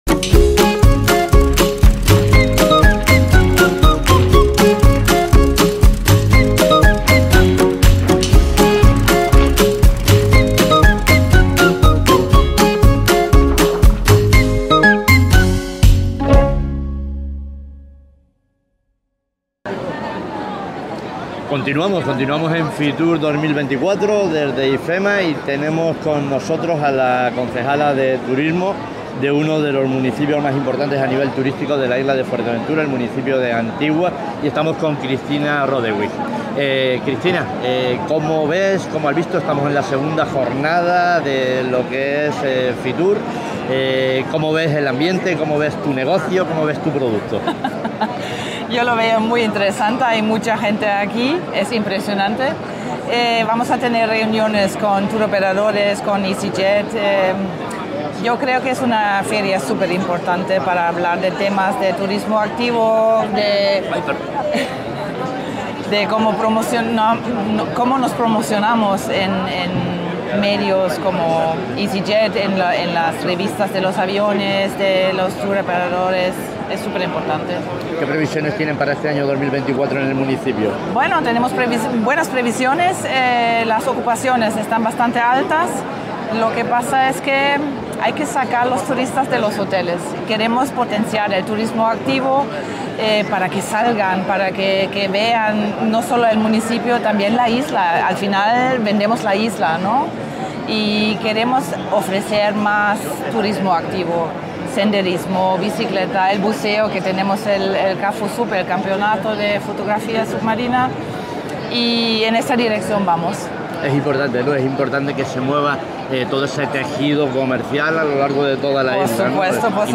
Fitur 2024: Entrevista a Kristina Rodewing
Entrevistamos a la concejala del municipio de Antigua, Kristina Rodewing en Fitur 2024.
fitur-2024-entrevista-a-kristina-rodewing.mp3